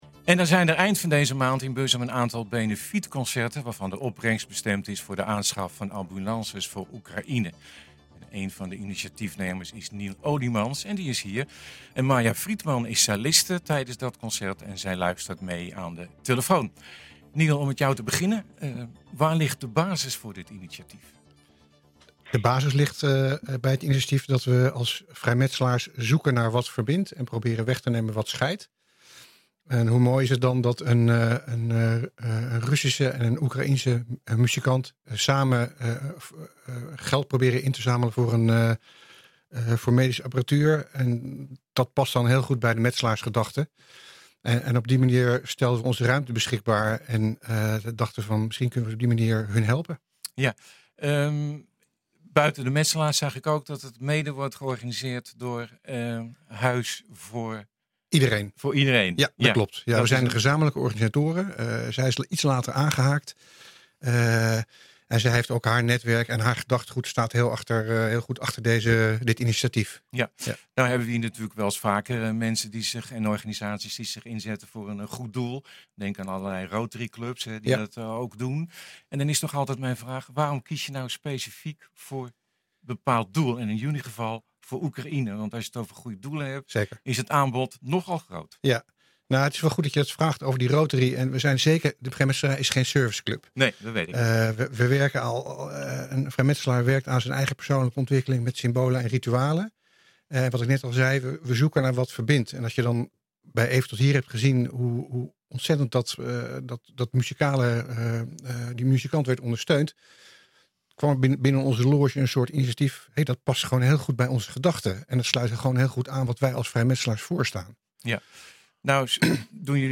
luistert mee  aan de telefoon